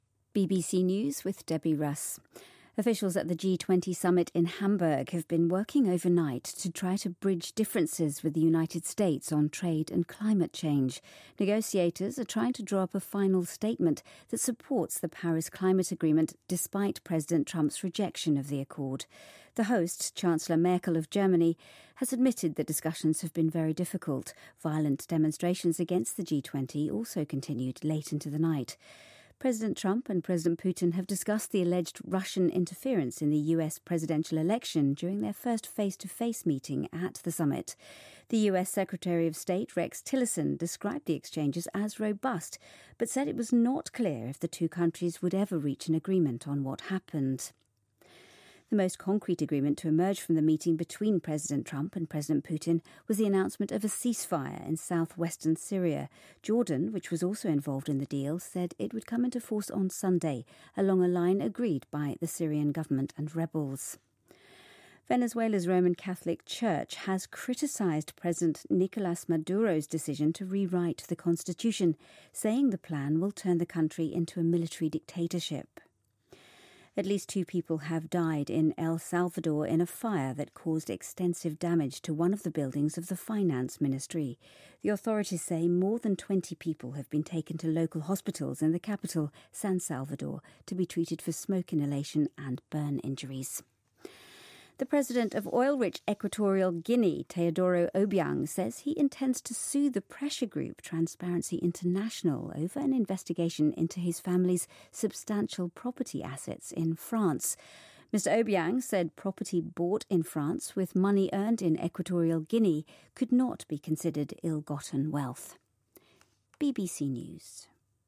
BBC news,特朗普与普京在G20汉堡峰会首次会晤